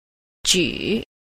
a. 舉 – jǔ – cử